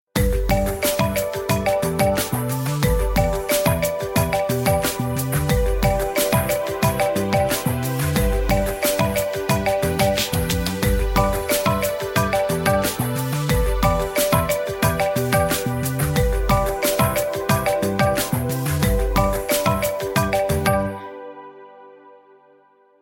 i-phone-ringtone_24837.mp3